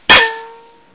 pain100_2.wav